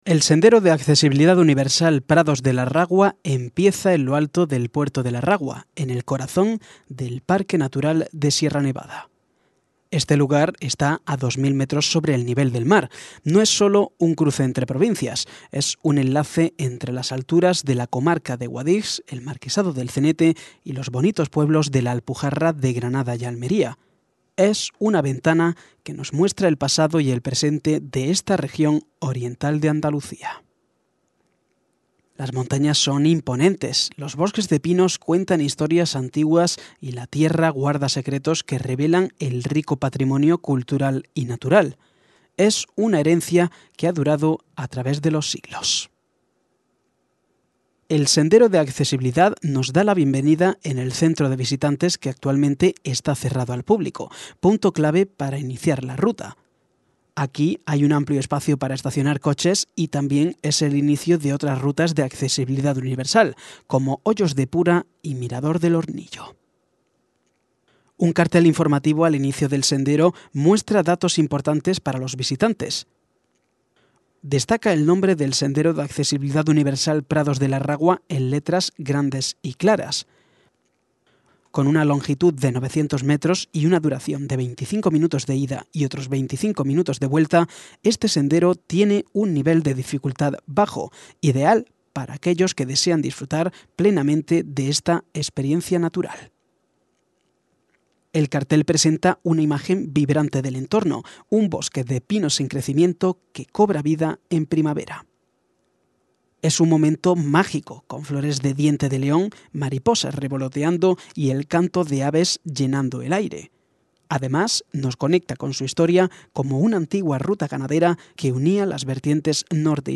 Audiodescripción de la Ruta “Prados de la Ragua”